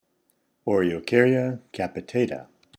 Pronunciation/Pronunciación:
O-re-o-cár-ya  ca-pi-tà-ta